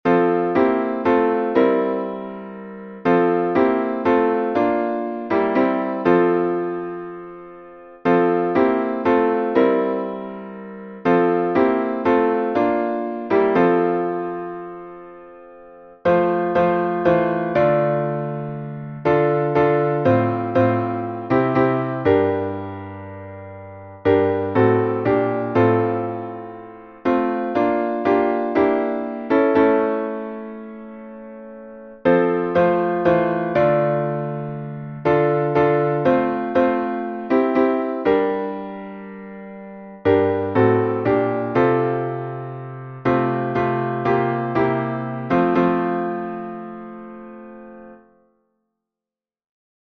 salmo_21B_instrumental.mp3